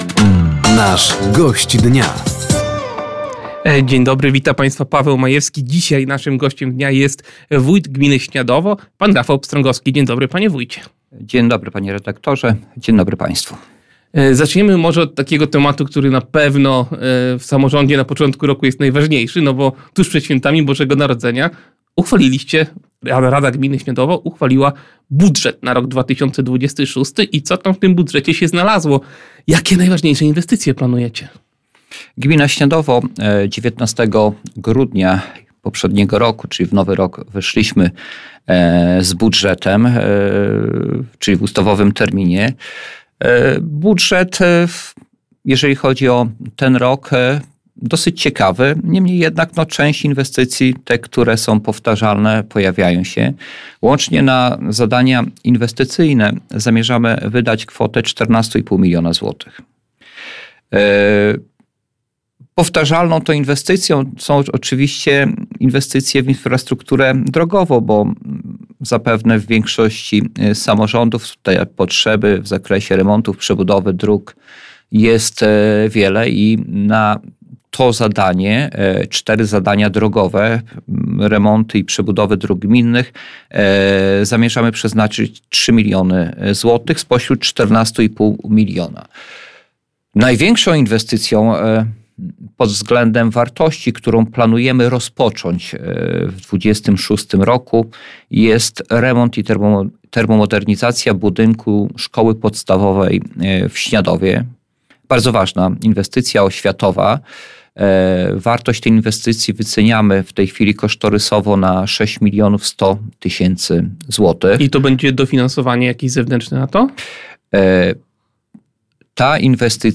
Gościem Dnia Radia Nadzieja w poniedziałek (26.01) był wójt gminy Śniadowo Rafał Pstrągowski. Tematem rozmowy był między innymi budżet gminy na ten rok, planowane inwestycje oraz pozyskiwanie funduszy zewnętrznych.